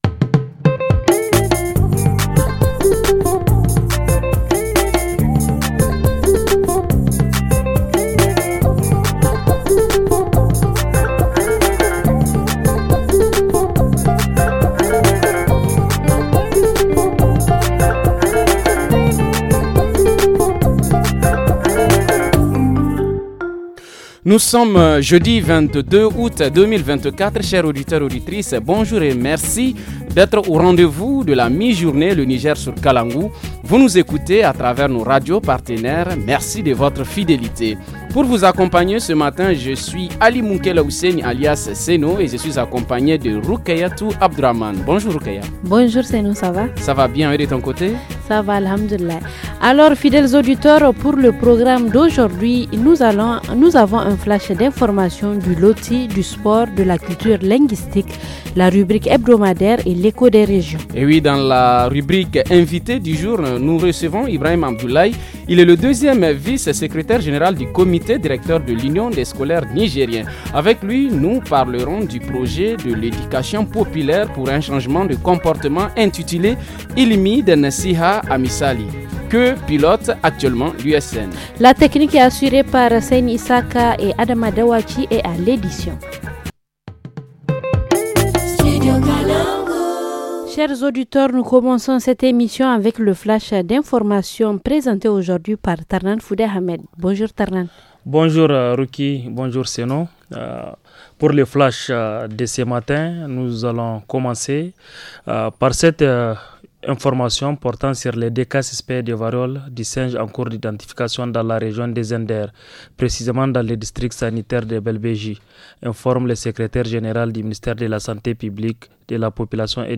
1. Le titre « Kaunar juna », de la chorale universitaire.